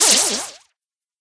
bea_atk_hit_01.ogg